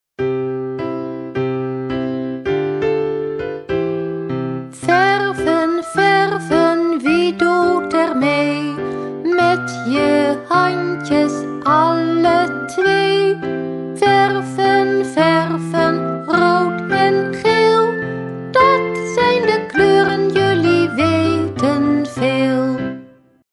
liedjes voor peuters en kleuters
Zang en begeleiding